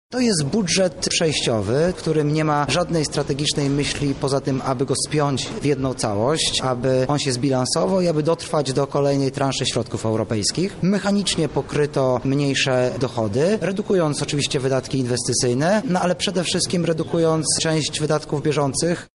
Taką decyzję klubu tłumaczy jego przewodniczący – Artur Soboń